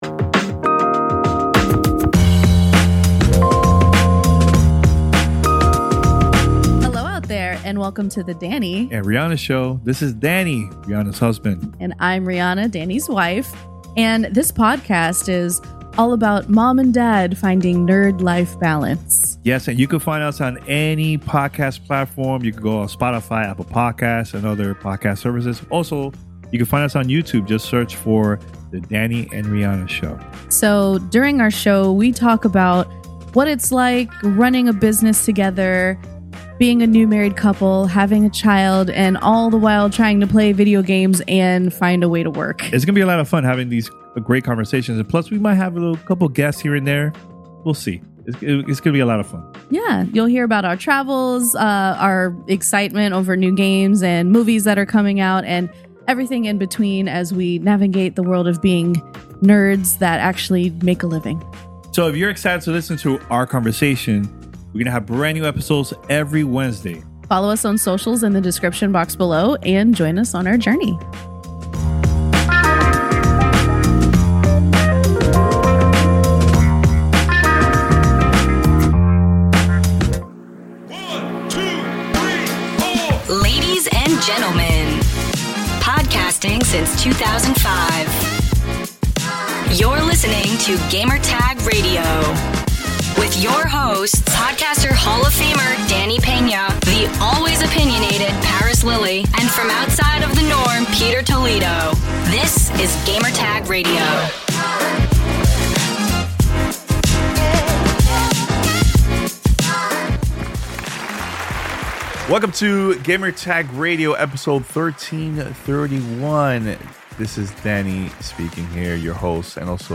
Games Backlog & Unknown 9: Awakening Interview
This week on Gamertag Radio - How bad is your backlog of games? Fallout TV Series trailer and Borderlands movie trailer reactions. Interview with Reflector Entertainment about Unknown 9: Awakening.